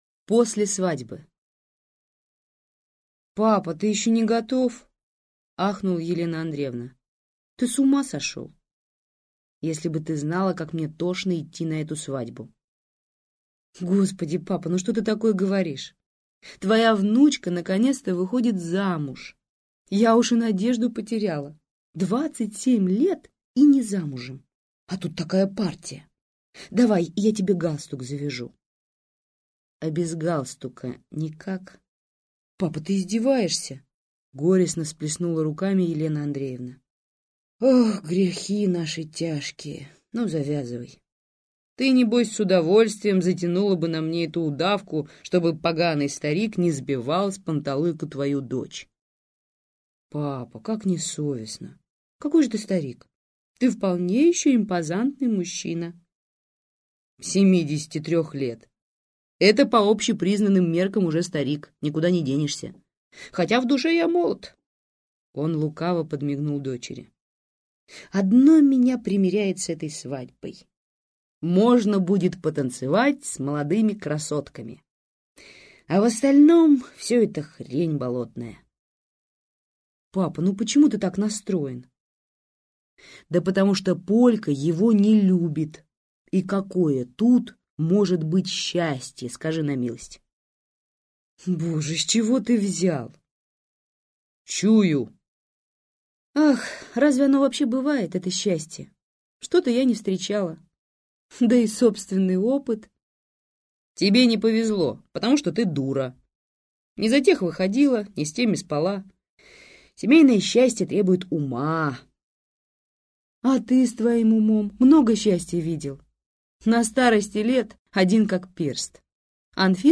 Аудиокнига Шалый малый | Библиотека аудиокниг